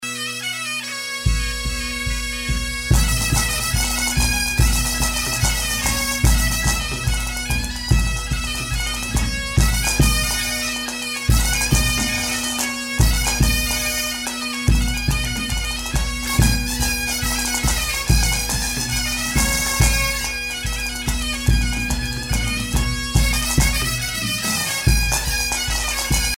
danse : dérobées ;
Pièce musicale éditée